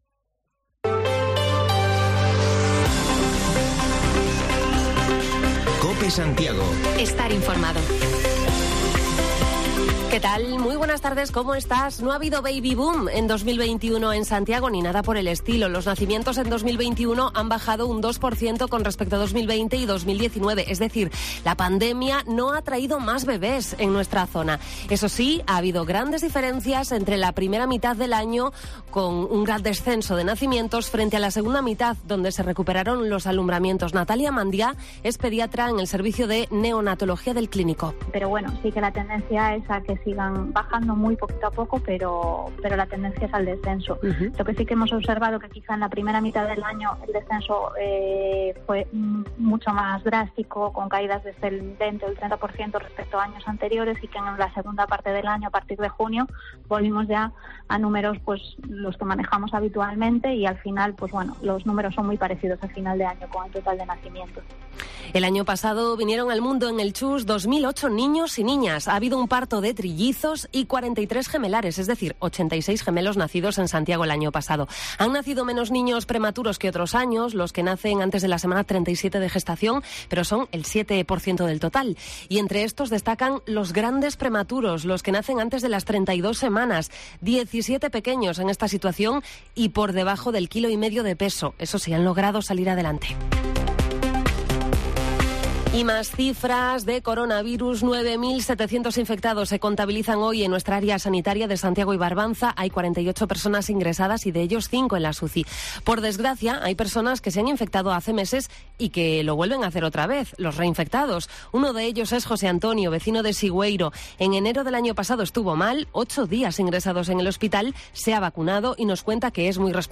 Repaso a la actualidad en Santiago y Barbanza: la vacunación pediátrica en el Gaiás y escuchamos el testimonio de un reinfectado